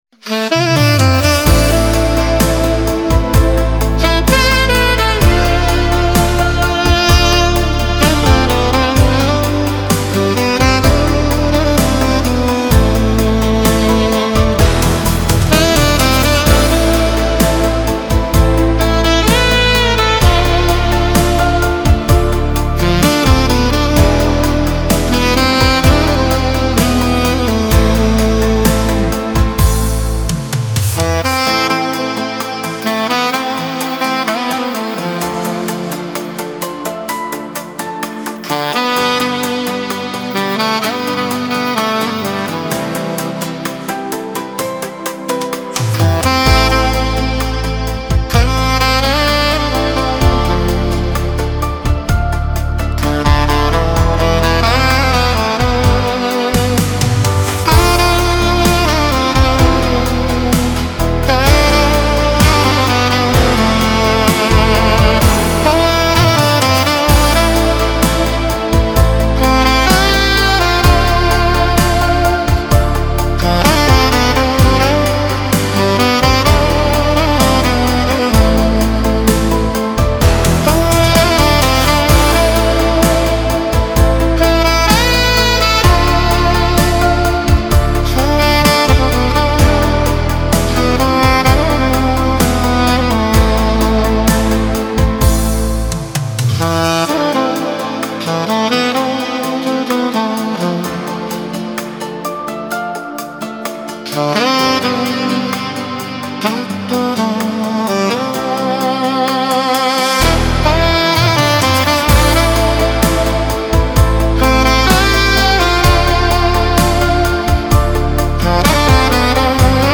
Instrumentalversion